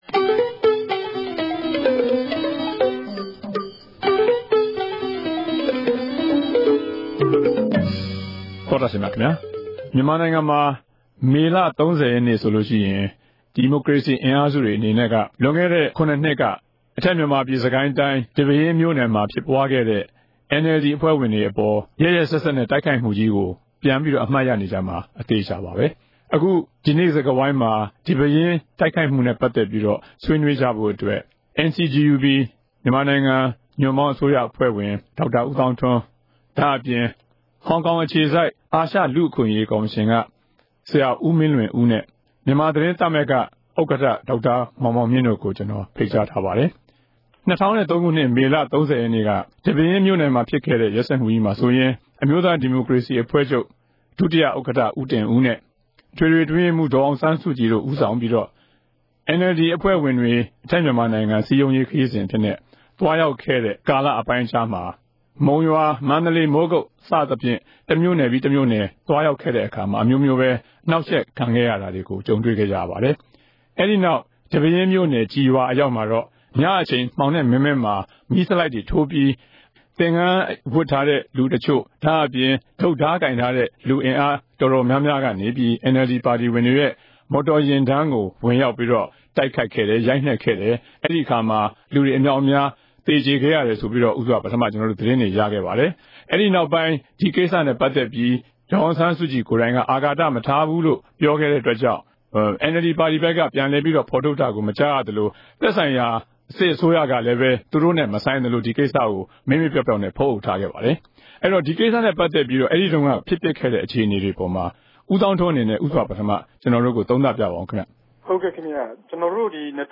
တနဂဿေိံစြကားဝိုင်း။